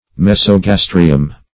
Search Result for " mesogastrium" : The Collaborative International Dictionary of English v.0.48: Mesogastrium \Mes`o*gas"tri*um\, n. [NL.